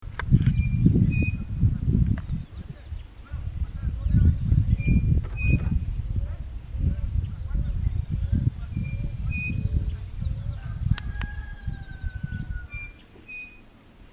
Striped Cuckoo (Tapera naevia)
Life Stage: Adult
Condition: Wild
Certainty: Observed, Recorded vocal